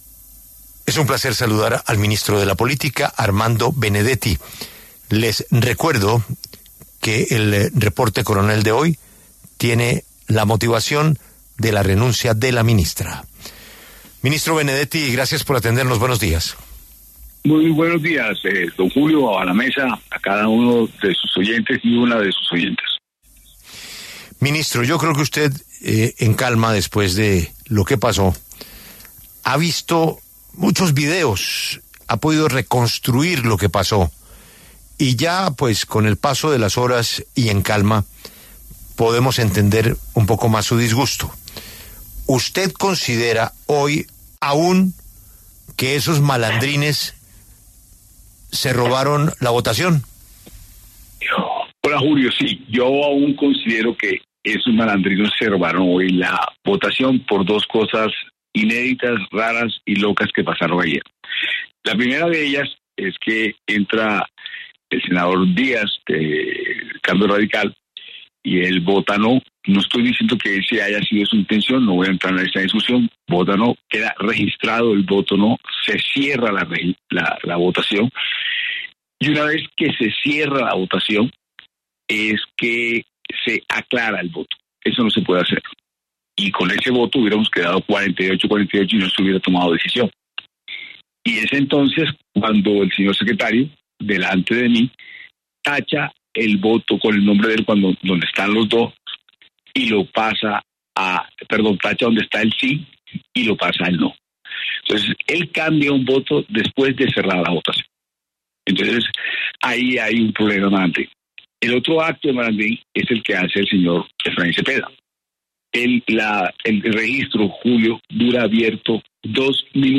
El ministro Armando Benedetti conversó con La W tras el hundimiento de la consulta popular, lo que revive la reforma laboral.
Habló Armando Benedetti, ministro del Interior, sobre el hundimiento de la consulta popular